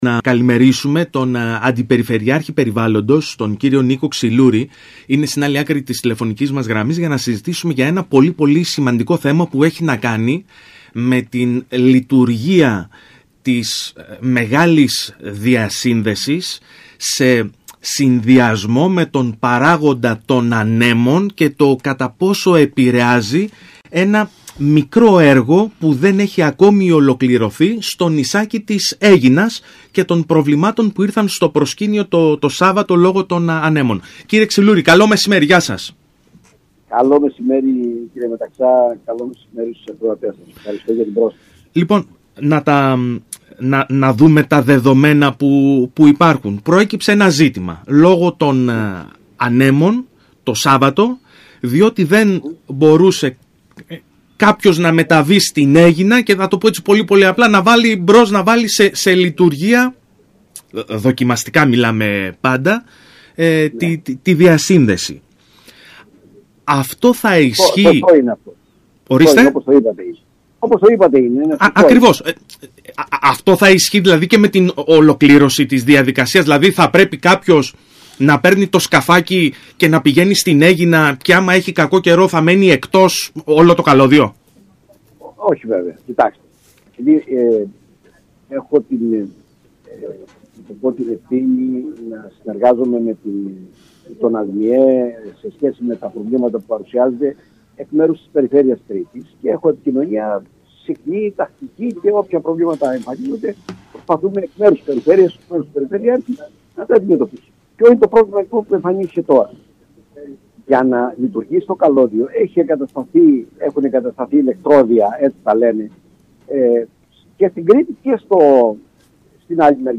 δήλωσε στο ραδιόφωνο του ΣΚΑΪ Κρήτης 92,1